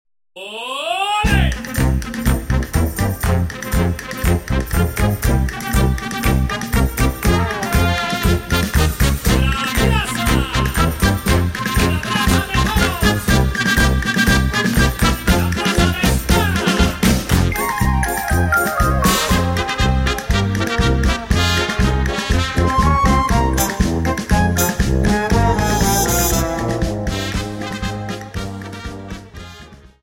Dance: Paso Doble 60 Song